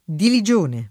dilegione [dileJ1ne] o diligione [